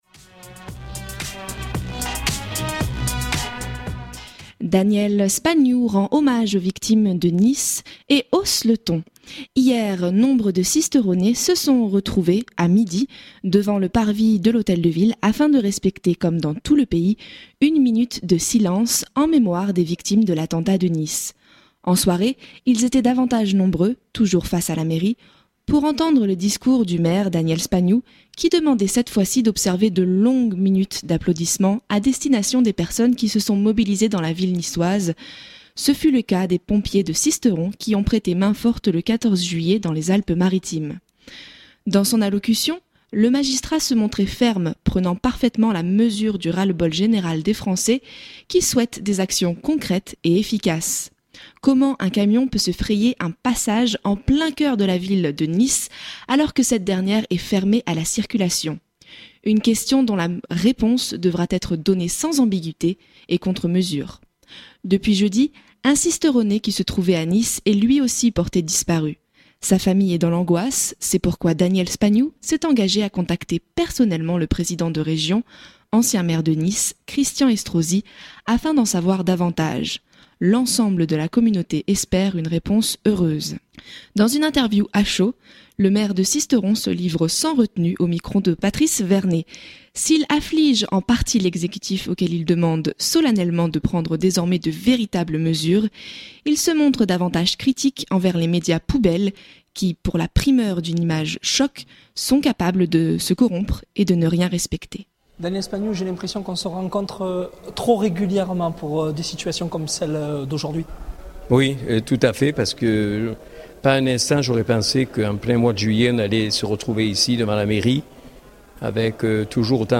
En soirée, ils étaient davantage nombreux, toujours face à la mairie, pour entendre le discours du maire Daniel Spagnou qui demandait cette fois-ci d’observer de longues minutes d’applaudissements à destination des personnes qui se sont mobilisées dans la ville niçoise, ce fut le cas des pompiers de Sisteron qui ont prêté main forte le 14 juillet dans les Alpes Maritimes. Dans son allocution, le magistrat se montrait ferme, prenant parfaitement la mesure du ras le bol général des français qui souhaitent des actions concrètes et efficaces.
Dans une interview à chaud, le maire de Sisteron se livre sans retenue